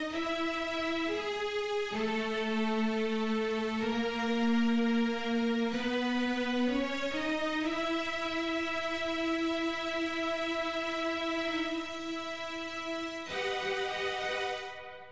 14 strings C1.wav